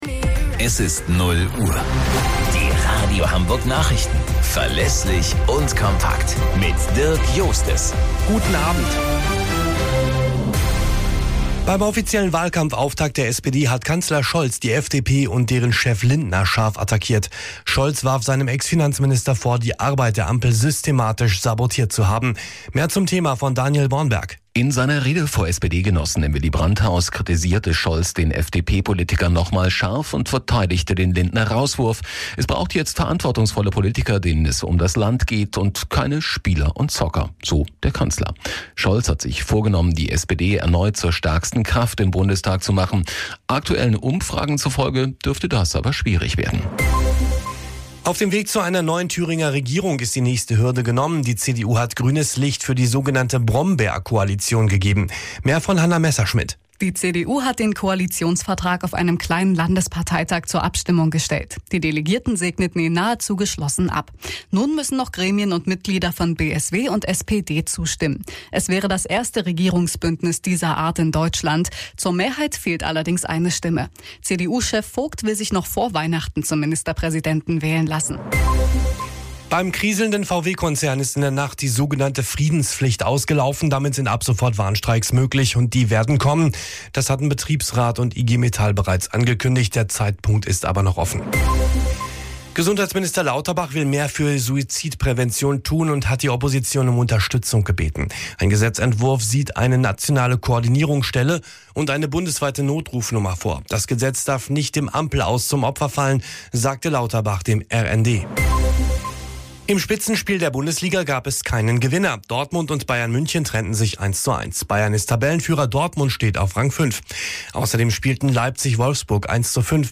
Radio Hamburg Nachrichten vom 01.12.2024 um 00 Uhr - 01.12.2024